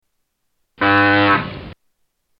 Harpo's horn